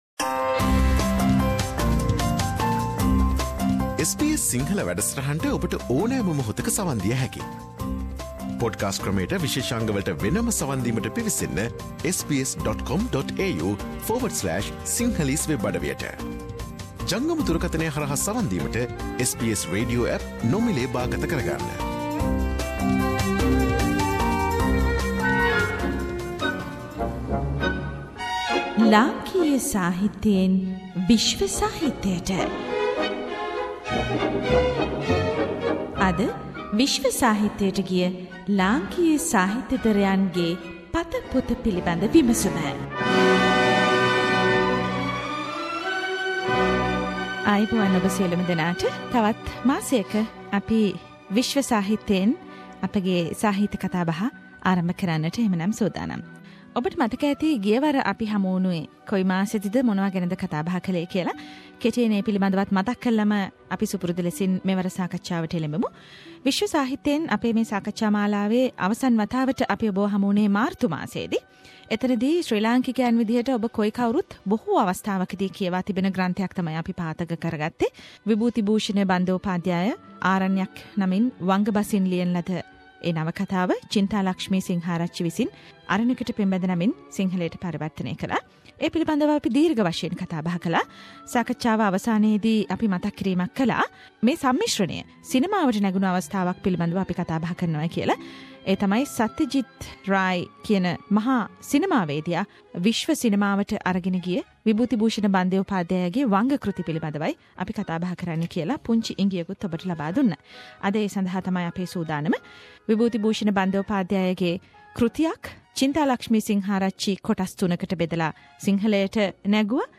“SBS Sinhala” monthly discussion forum of world literature